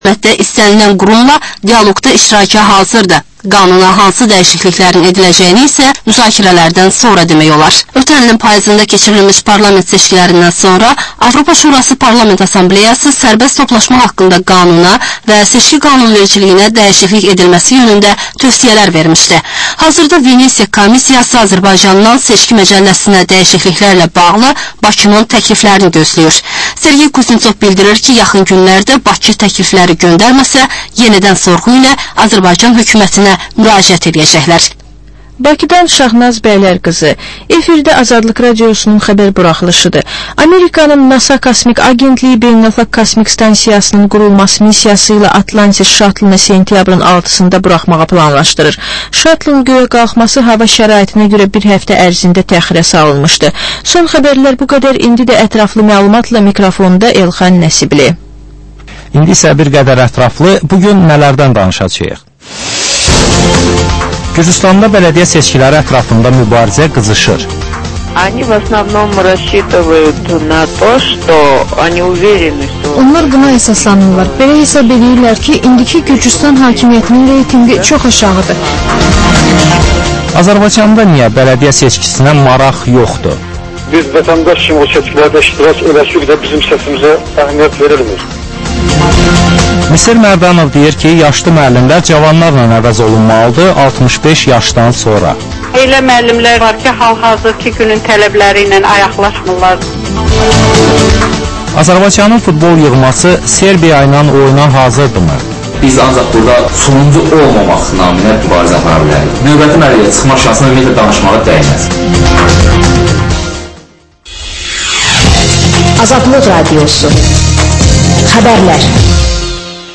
Reportaj, müsahibə, təhlil